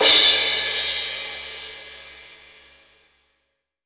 Index of /90_sSampleCDs/AKAI S6000 CD-ROM - Volume 3/Crash_Cymbal1/16-17_INCH_CRASH